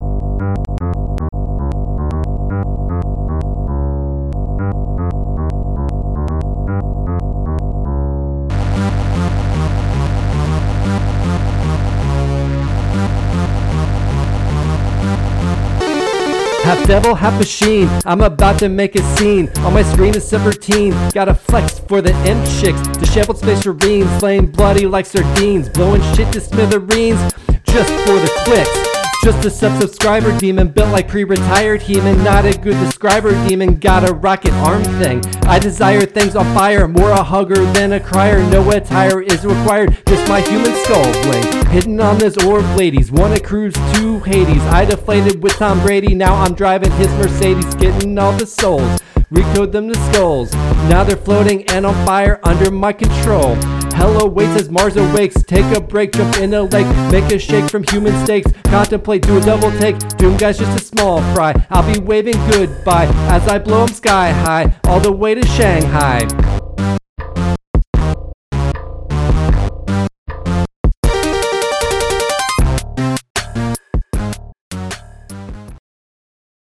Rap from Episode 42: Brutal Doom – Press any Button
Brutal-Doom-Rap.mp3